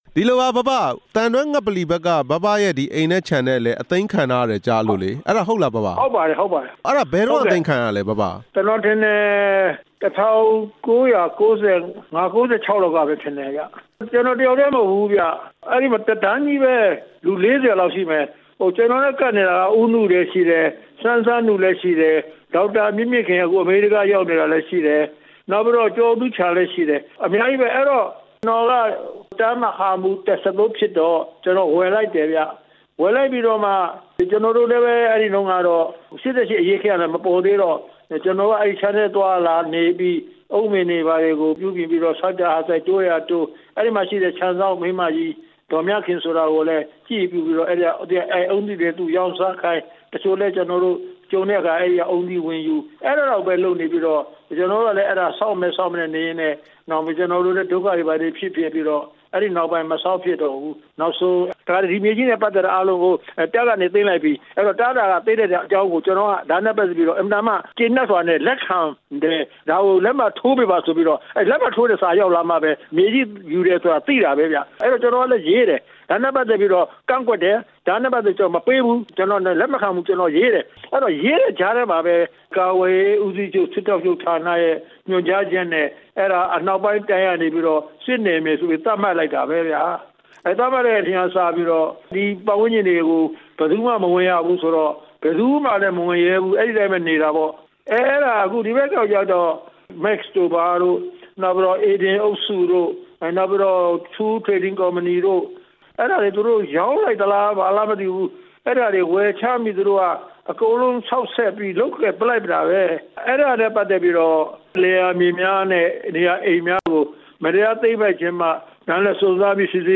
မြေသိမ်းခံရသူ ဗိုလ်ချုပ်ကြီးဟောင်း သူရ ဦးတင်ဦးနဲ့ မေးမြန်းချက်